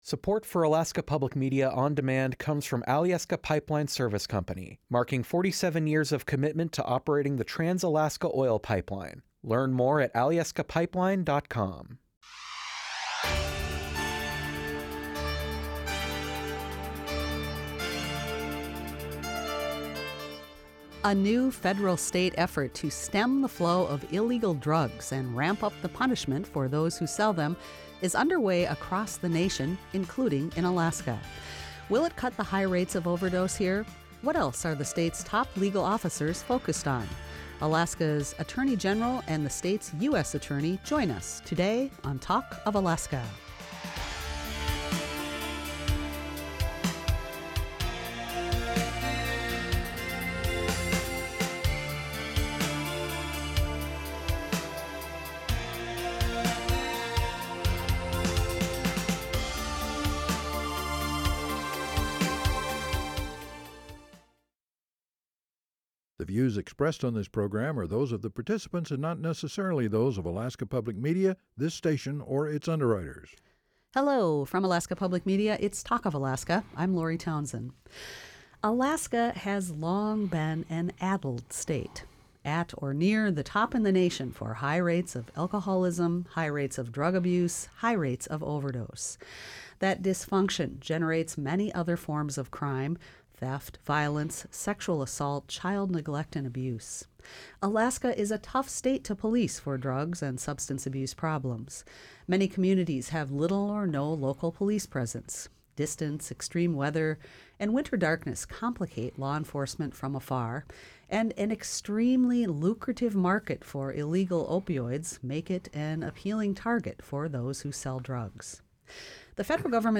Alaska’s only statewide call-in forum for discussing the issues impacting life on the Last Frontier.